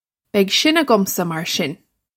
Pronunciation for how to say
Beg shin a-gum-sah, marr shin.
This is an approximate phonetic pronunciation of the phrase.